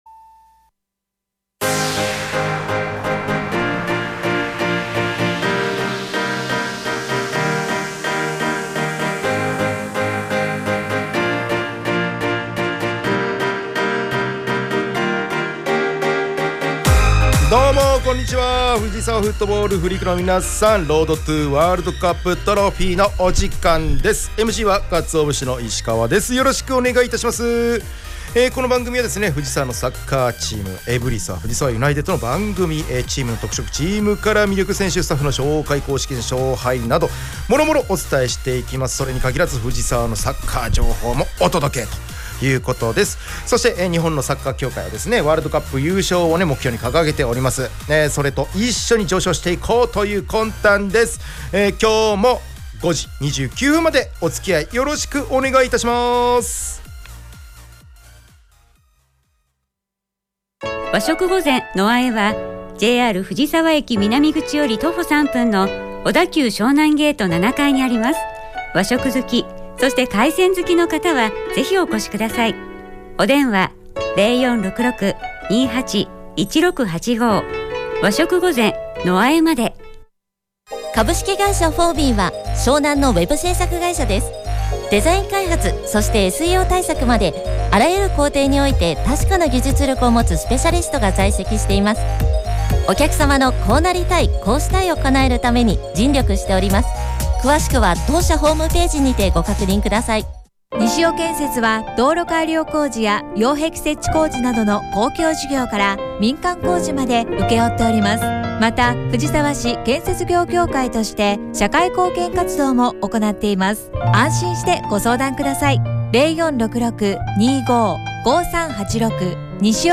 エブリサ藤沢ユナイテッドが提供する藤沢サッカー専門ラジオ番組『Road to WC Trophy』の第2期の第35回放送が11月29日(金)17時に行われました☆